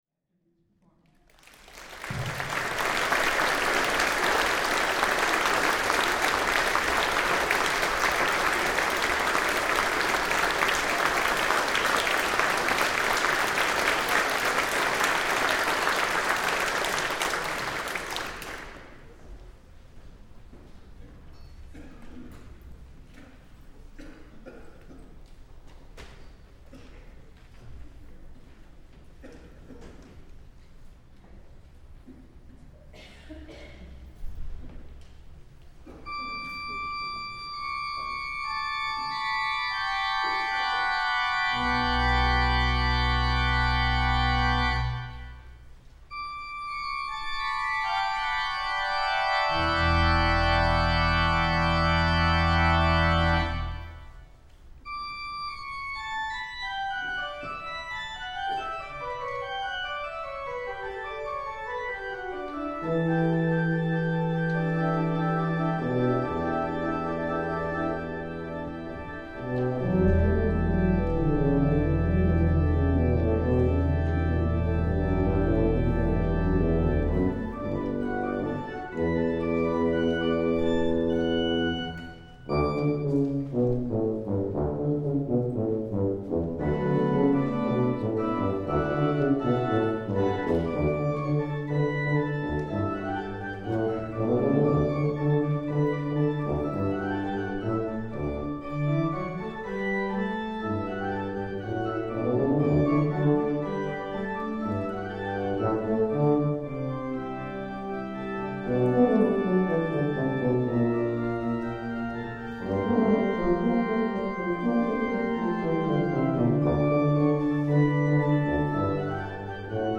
tuba
organ.